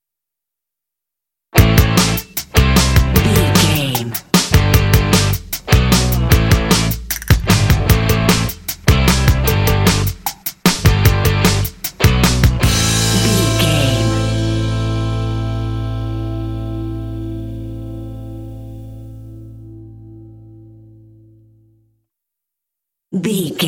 Fast paced
Aeolian/Minor
fun
happy
bouncy
groovy
drums
electric guitar
bass guitar
alternative
indie